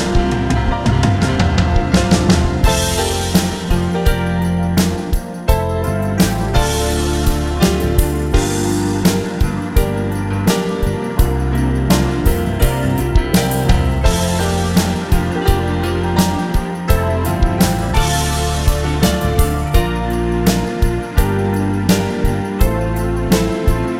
Two Semitones Down Soundtracks 2:57 Buy £1.50